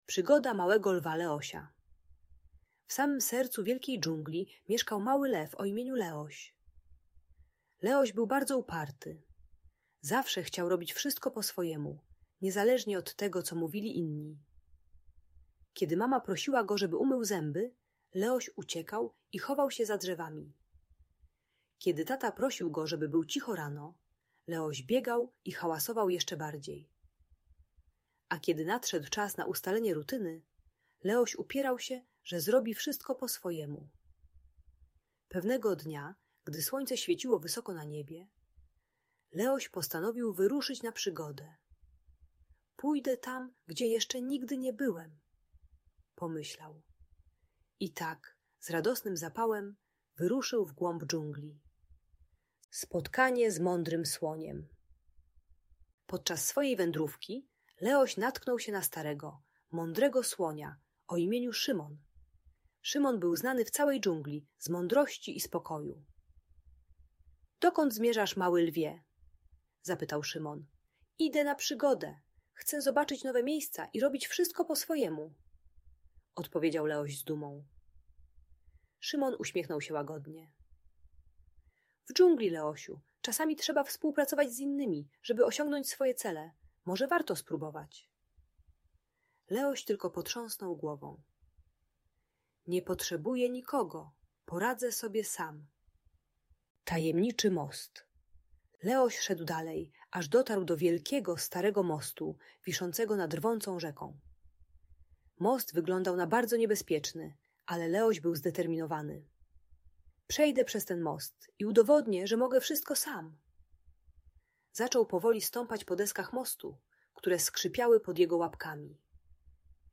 Przygoda Małego Lwa Leosia - Niepokojące zachowania | Audiobajka